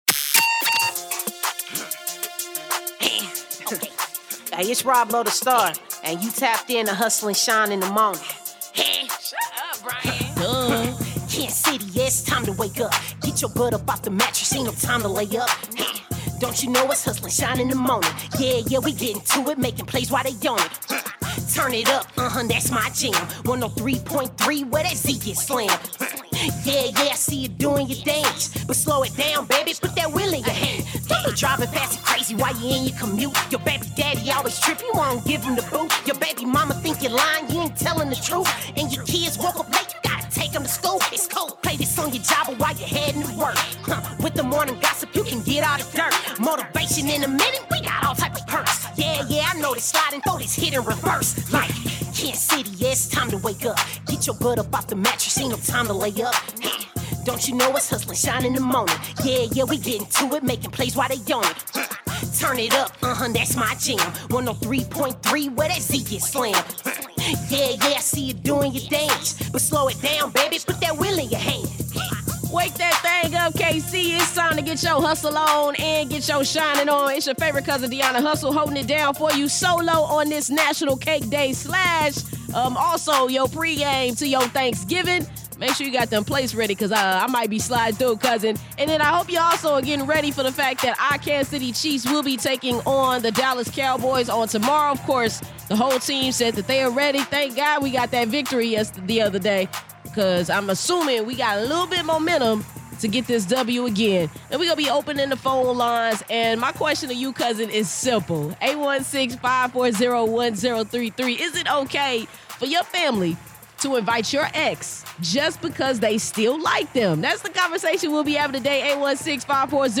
This episode’s highlights feature: Local News: Chiefs’ Pacheco set to return from injury for Thanksgiving game against Cowboys Callers share if it’s okay for their family to invite their exes. THE MORNING GOSSIP: NickCannon speaks about his children defending themselves against others and Lil Kim landed #1 on Billboard’s Greatest Album Covers.